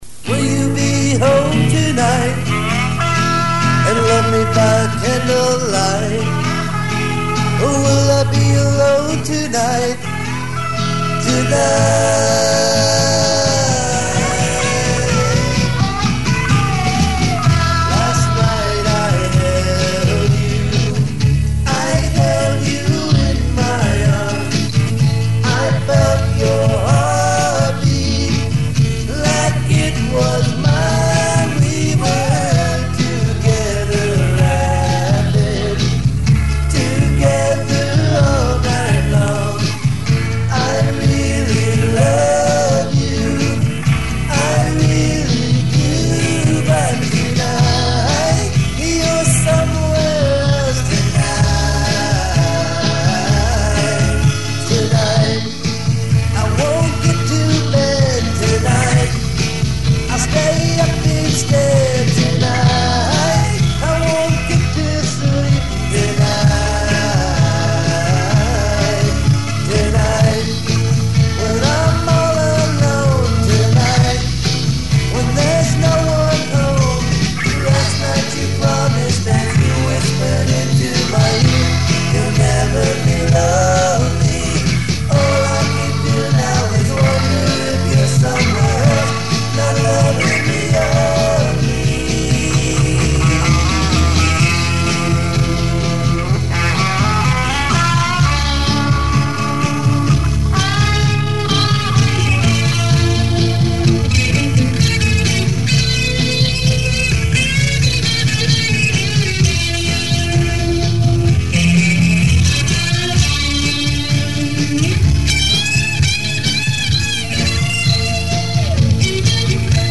played the lead guitar and helped to provide harmony vocals.